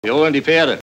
Sound file of German dubbing actor